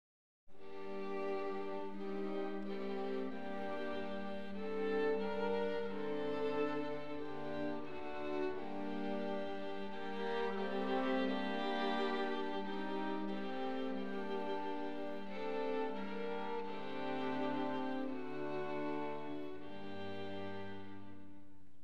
كتب شوبرت هذه الحركة الأكثر شهرة بسرعة أندانتيه (بطيء) للتيمبو وجعلها بقالب لحن أساسي وخمسة تنوعيات عليه، وكل جزء من التنويعات يُعاد عزفه مره اخرى، واختار لحنها الأساسي المكتوب بمفتاح G ماينر ليكون هو نفسه اللحن المهيب لقدوم الموت في الكوردات الأولى لأغنيته الموت والعذراء، يستطيع المستمع تمييزه بشكل واضح: